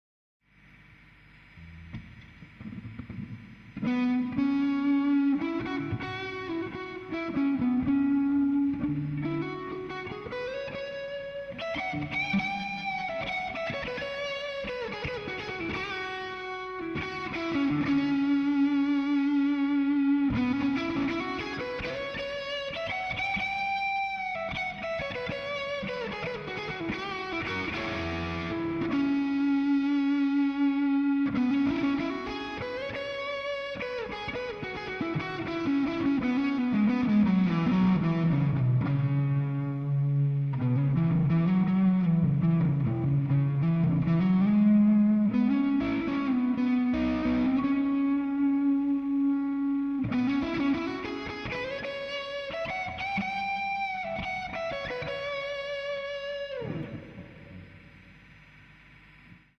� �������: �� ��� ������ ����� ����� �� �������� � ��������� modeller_with_2000rur_drive.ogg (258.51 �� - ��������� 93 ���.)